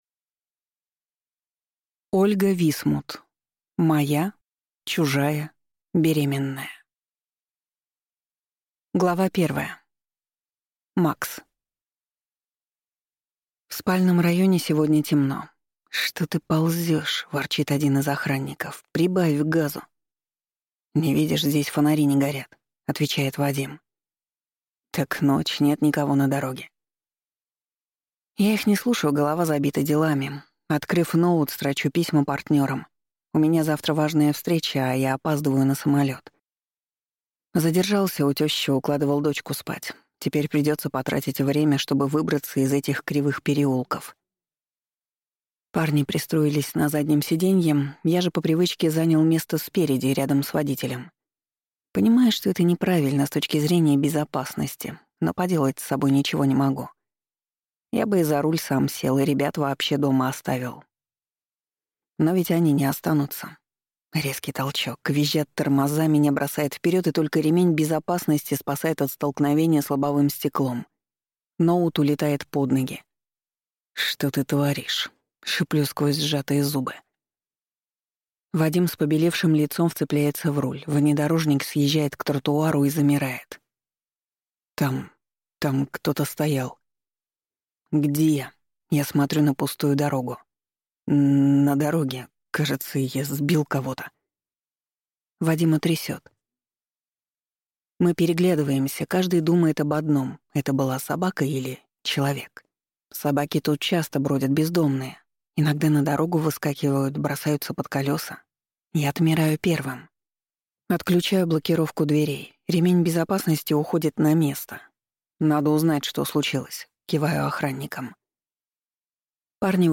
Аудиокнига Моя. Чужая. Беременная | Библиотека аудиокниг